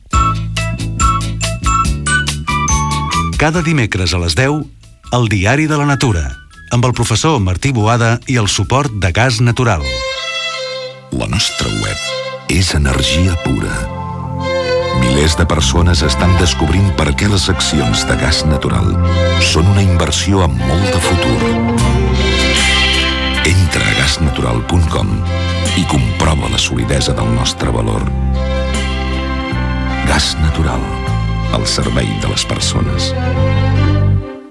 Promoció
Banda FM